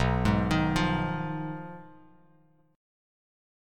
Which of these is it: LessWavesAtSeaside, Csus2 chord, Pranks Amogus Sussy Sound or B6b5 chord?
B6b5 chord